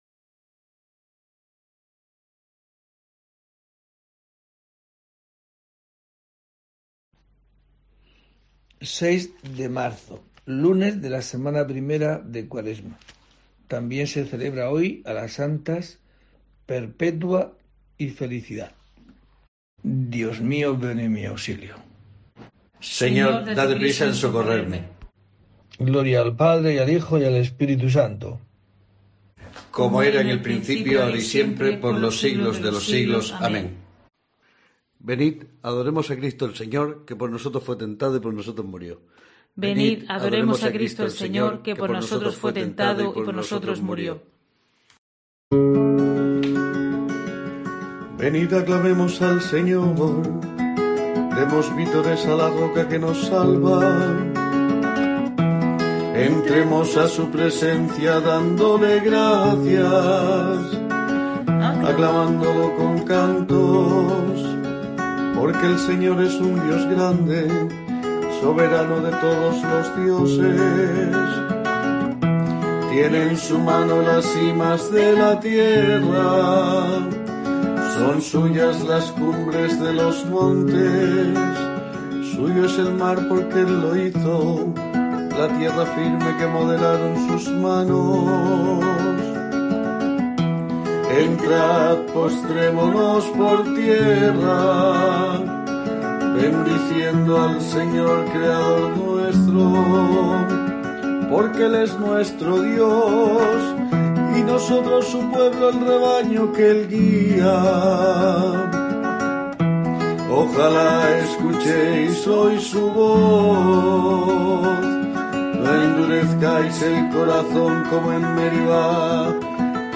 Laudes